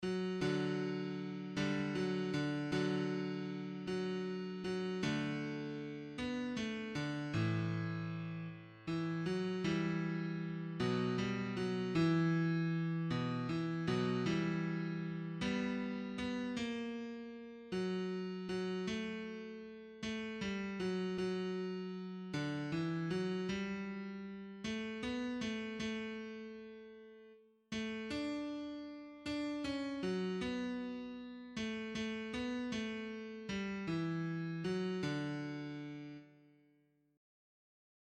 L’air que vous entendez est celui de la « Bourgado escarido » extrait de la Pastorale Maurel. Il évoque Bethléem, bourgade pauvre.